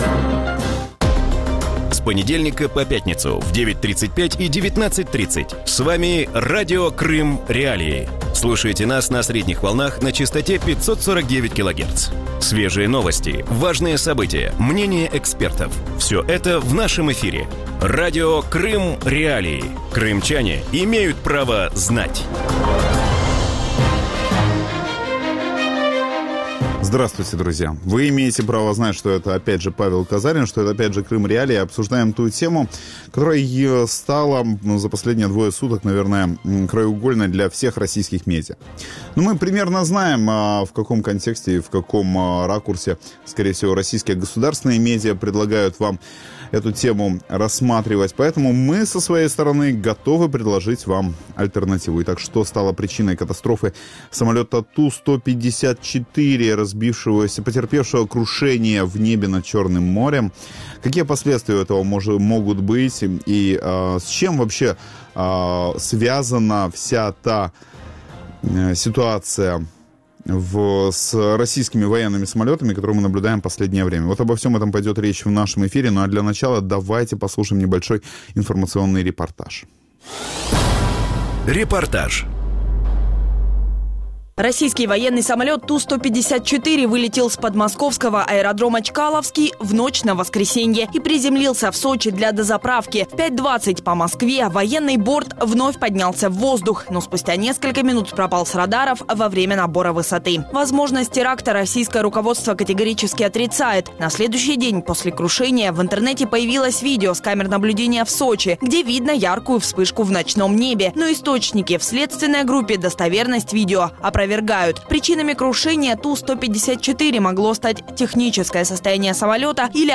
У вечірньому ефірі Радіо Крим.Реалії обговорюють можливі причини і наслідки катастрофи російського військового літака. Чому ТУ-154 розбився над Чорним морем і чи може ця авіакатастрофа вплинути на зовнішню політику Росії?